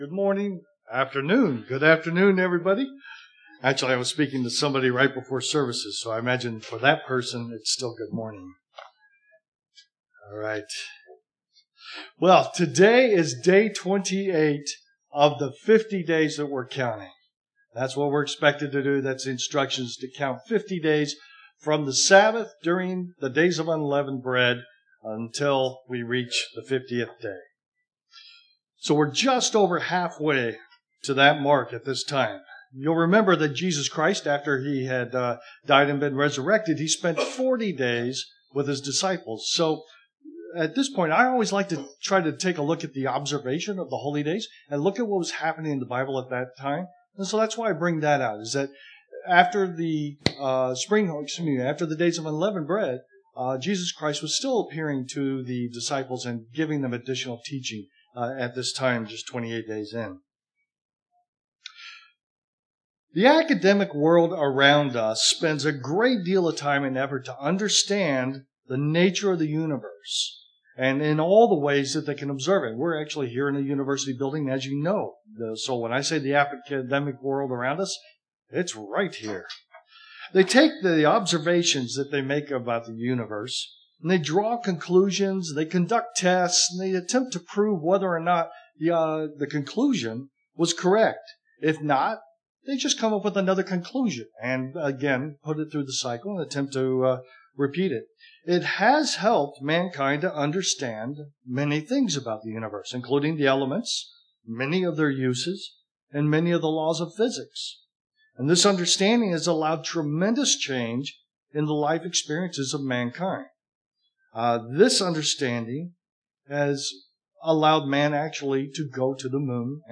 This split sermon takes a look at the tower of Babel, the giving of the law at Mount Sinai, the receiving of the Holy Spirit in Acts 2, and brings out some common threads the events share.